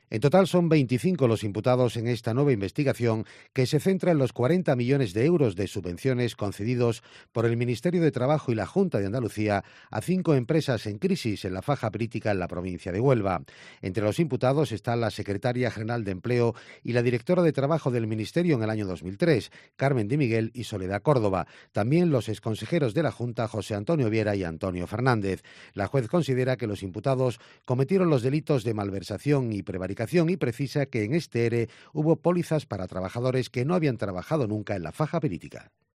desde COPE Sevilla